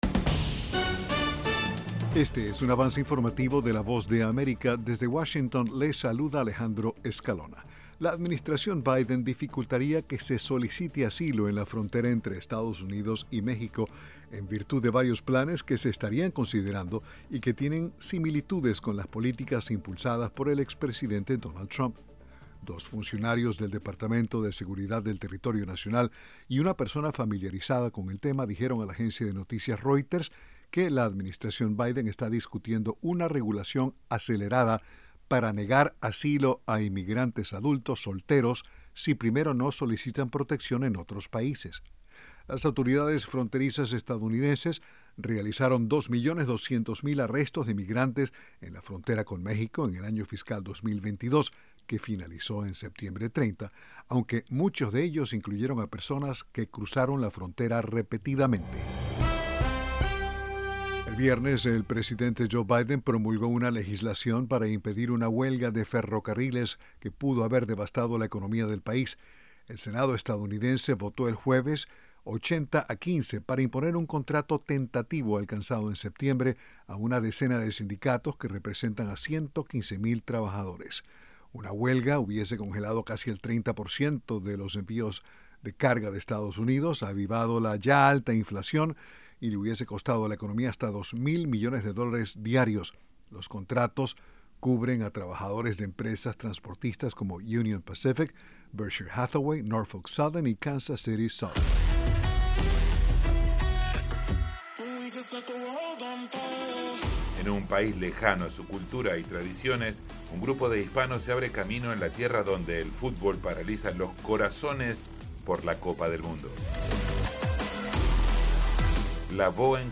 Este es un un avance informativo presentado por la Voz de América en Washington.